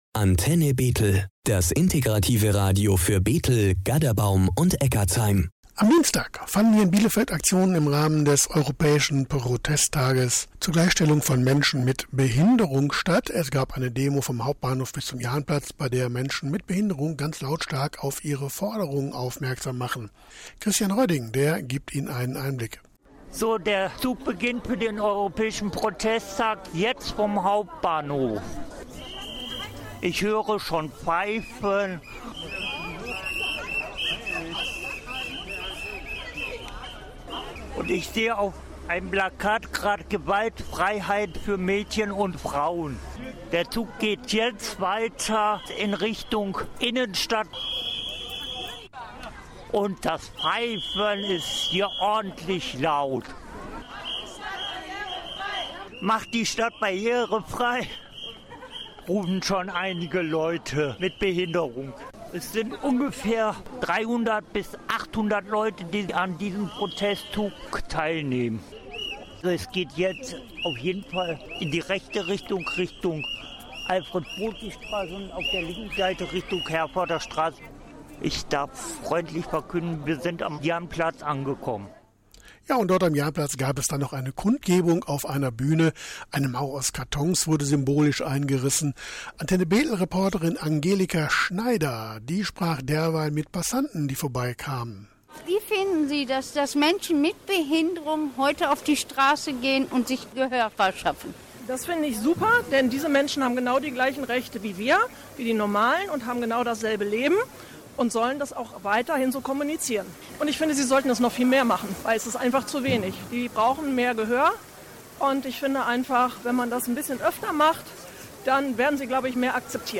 Der europäische Protesttag zur Gleichstellung von Menschen mit Behinderung wurde in Bielefeld bereits am Dienstag, den 30. April begangen. Hier eine Zusammenfassung der Beiträge unseres Reporterteams von diesem Tag: